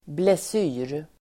Ladda ner uttalet
Uttal: [bles'yr:]